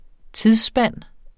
Udtale [ -ˌsbanˀ ]